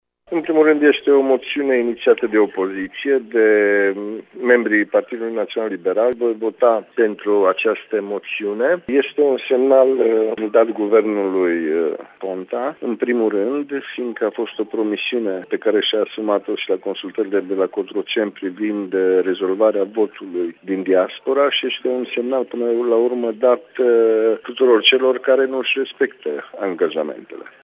Deputatul PNL de Mureș, Cristian Chirteș, crede că votul de mâine e necesar pentru a trage un semnal de alarmă la adresa premierului Victor Ponta, care nu a ”rezolvat” problema votului din Diaspora.